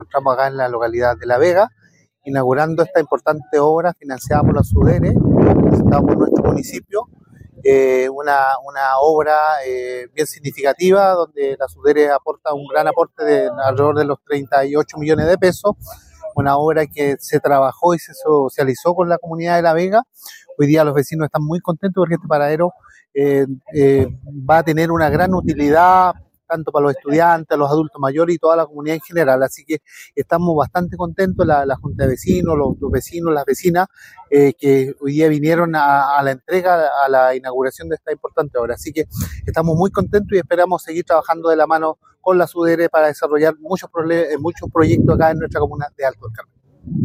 CRISTIAN-OLIVARES-ALCALDE-ALTO-DEL-CARMEN.mp3